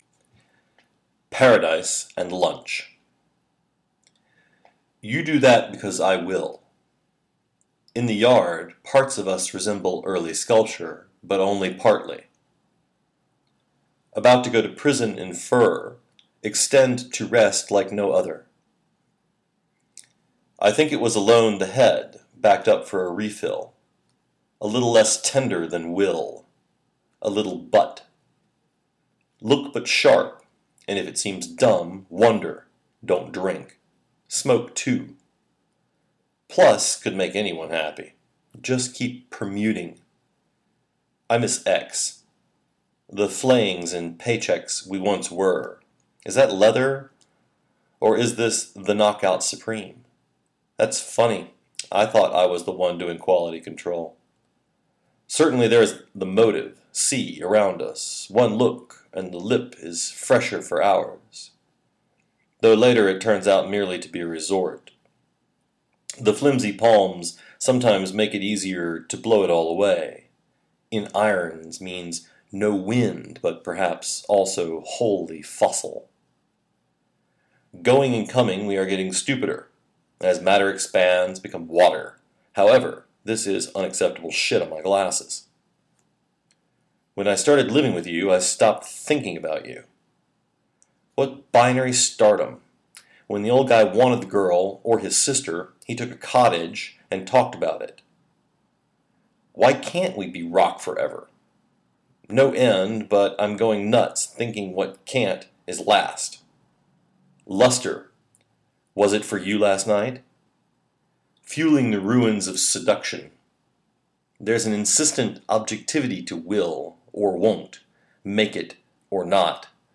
reading Jean Day - from IN THE AMERICAN TREE